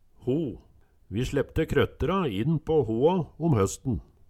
ho - Numedalsmål (en-US)